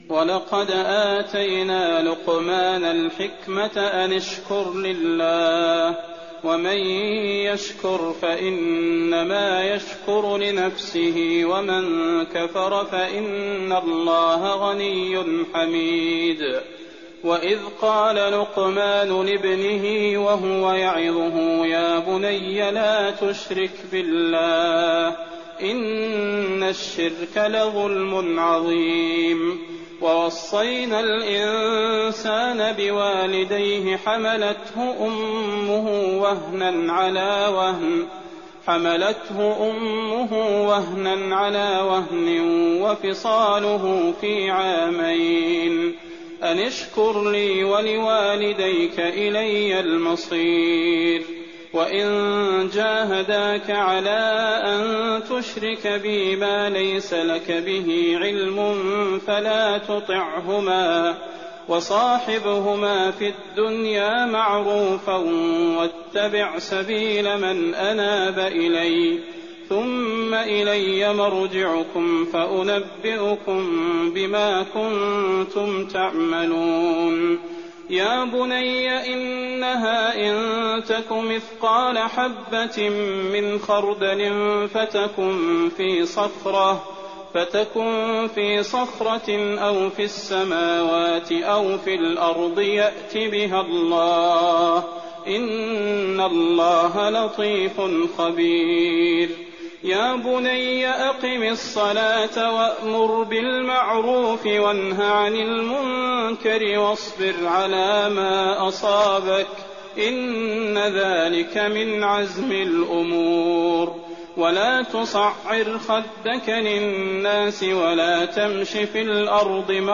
تراويح ليلة 20 رمضان 1419هـ من سور لقمان (12-34) والسجدة و الأحزاب (1-27) Taraweeh 20th night Ramadan 1419H from Surah Luqman and As-Sajda and Al-Ahzaab > تراويح الحرم النبوي عام 1419 🕌 > التراويح - تلاوات الحرمين